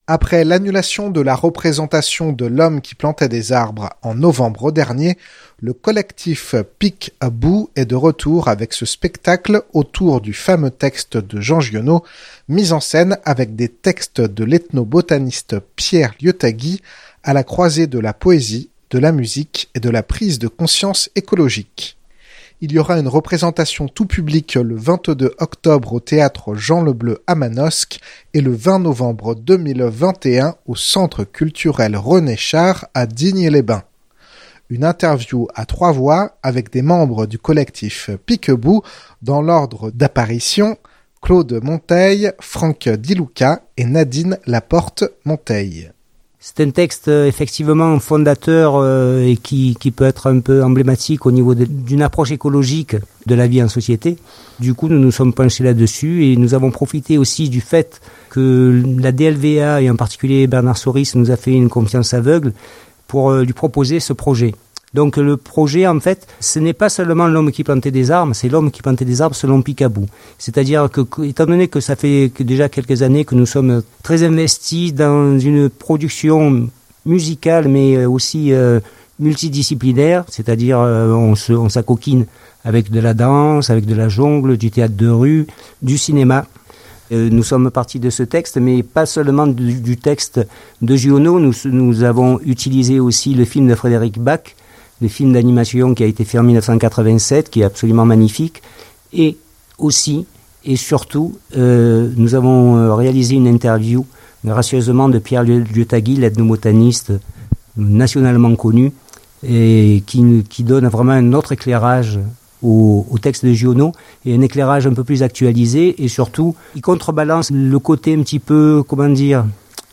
Une interview à 3 voix avec des membres de Peek A Boo